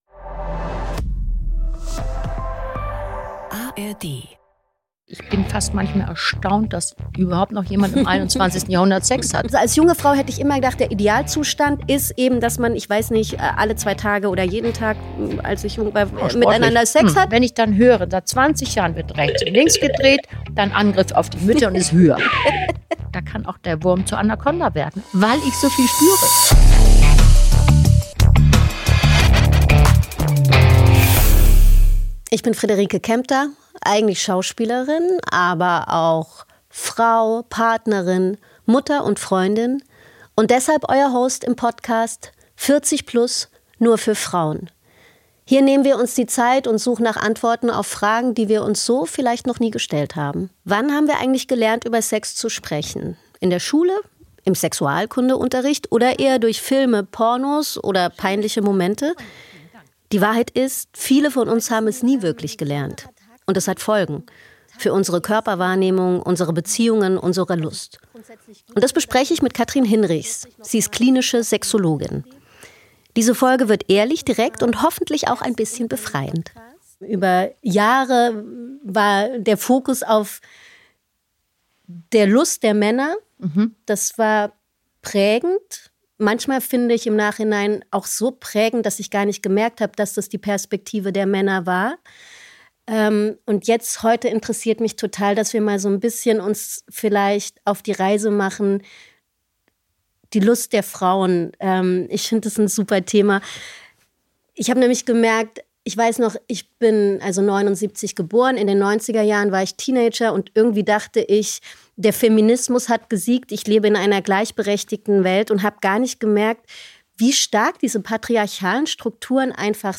In dieser Folge des SWR-Podcasts „40+ Nur für Frauen“ spricht die Schauspielerin Friederike Kempter